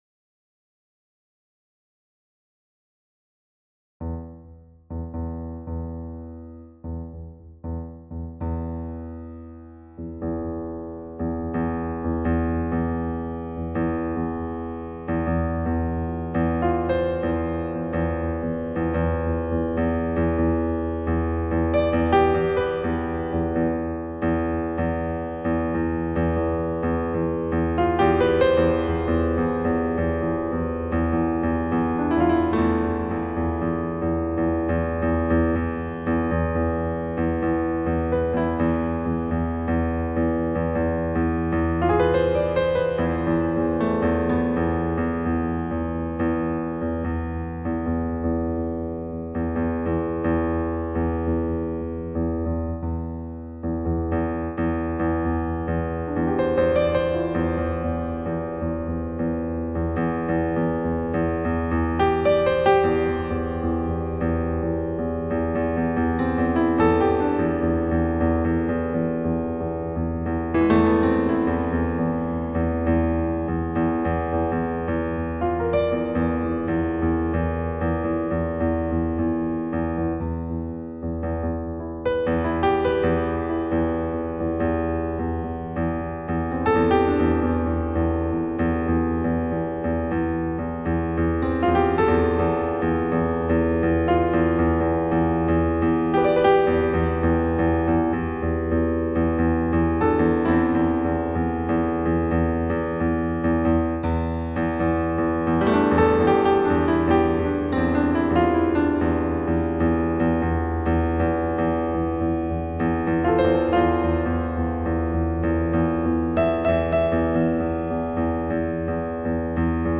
我这个音乐就是把两种感觉放一起而产生了多元化的概念。